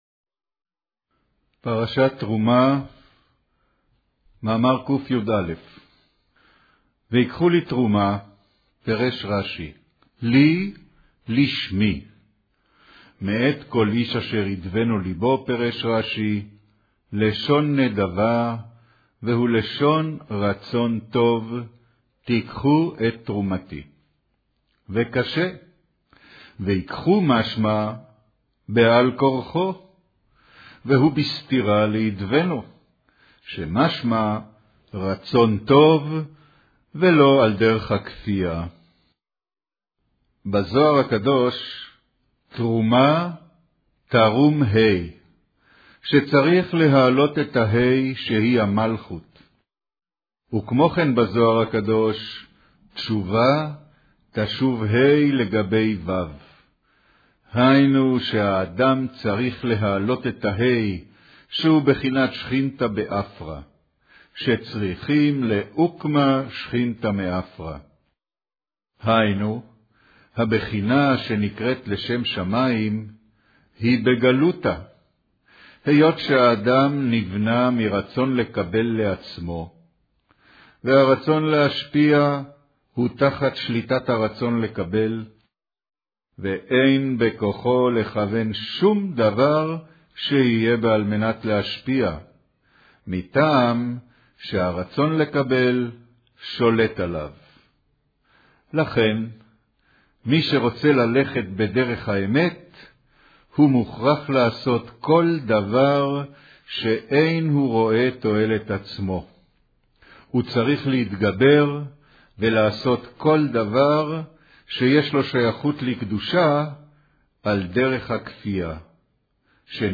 קריינות